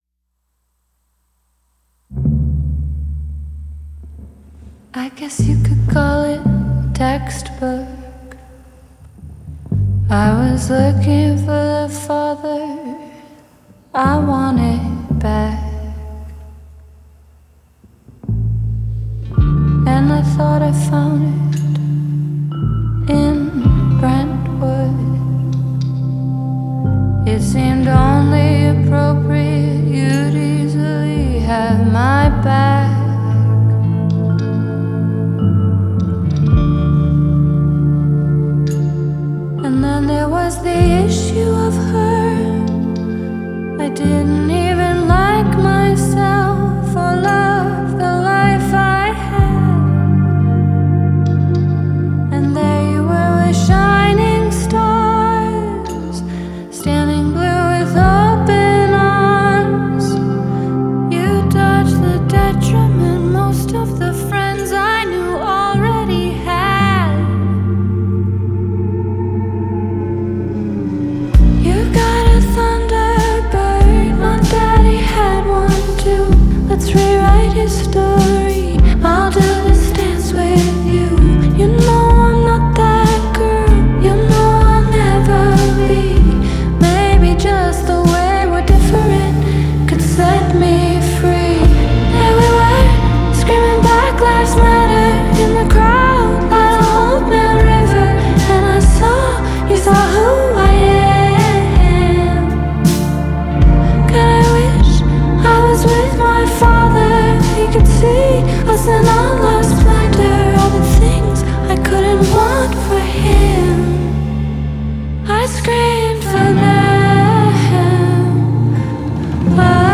AlternativePop / DreamPop / BaroquePop